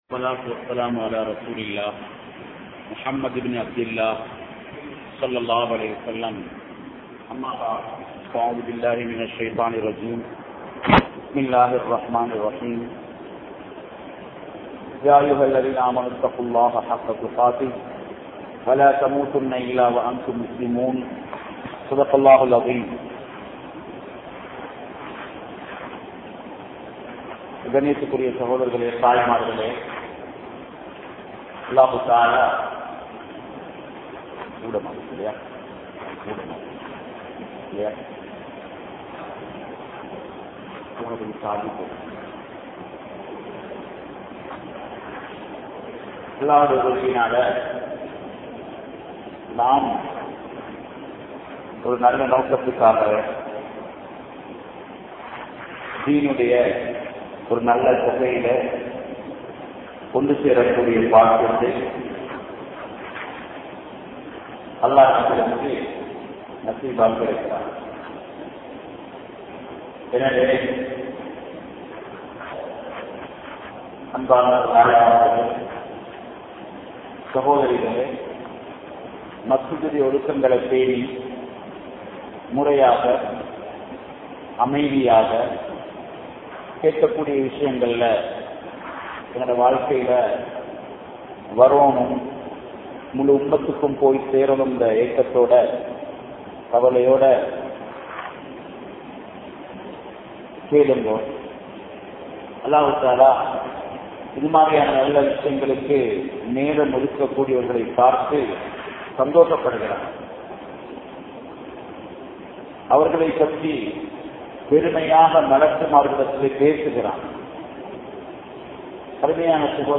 Veettu Soolalai Seeraakkungal (வீட்டு சூழலை சீராக்குங்கள்) | Audio Bayans | All Ceylon Muslim Youth Community | Addalaichenai
Kantala, Siraj Nagar 97 Salihath Jumua Masjidh